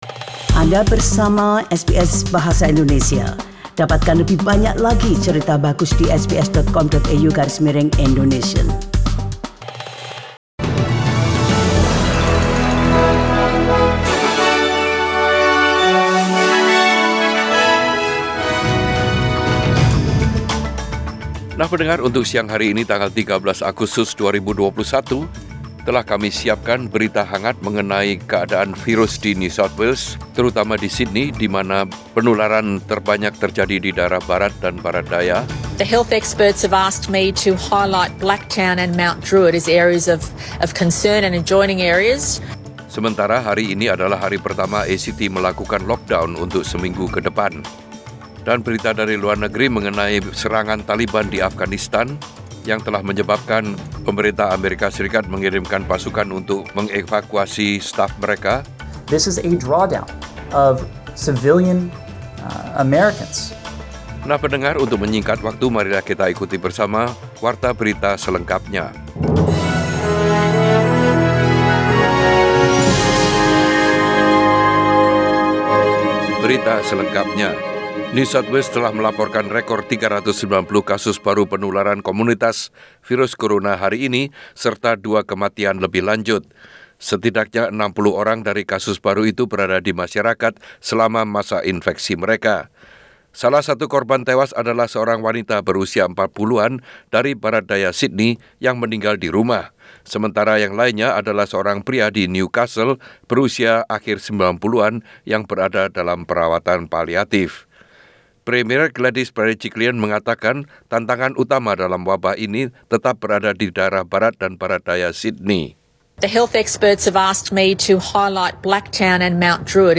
SBS Radio News in Bahasa Indonesia - 13 August 2021
Warta Berita Radio SBS Program Bahasa Indonesia Source: SBS